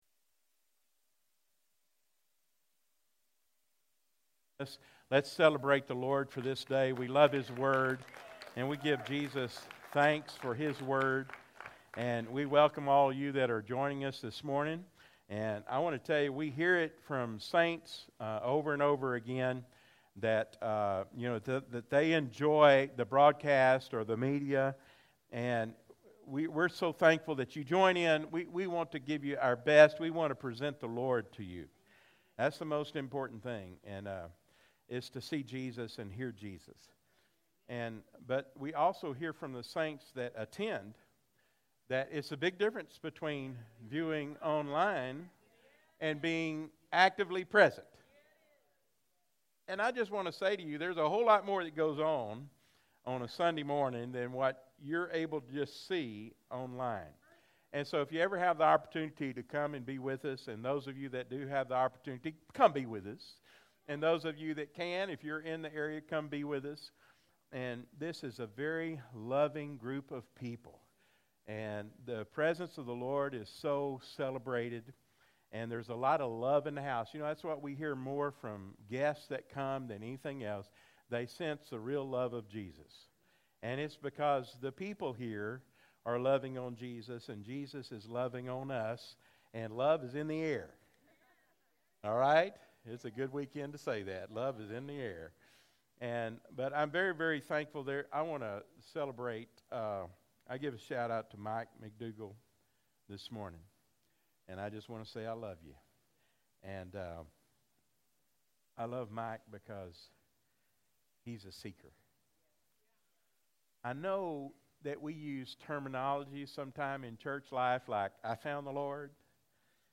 Freedom Life Fellowship Live Stream